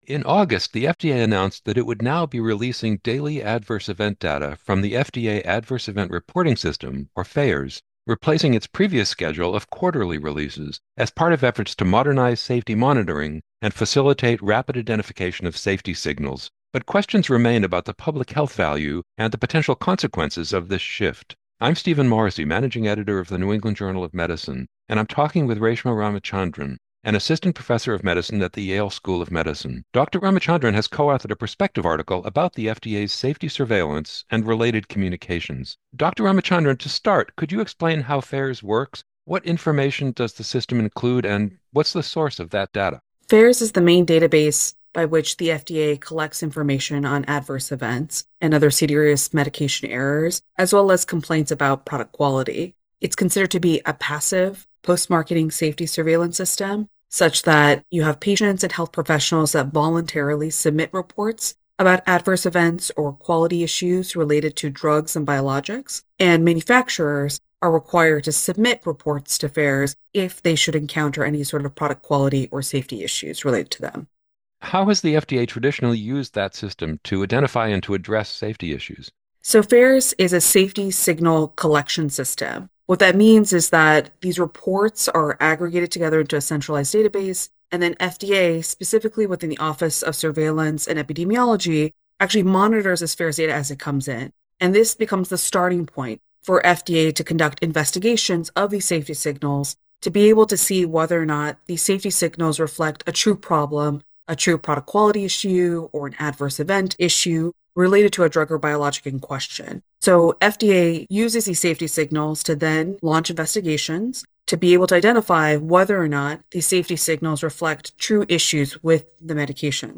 NEJM Interview